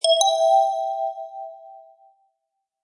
new_message.mp3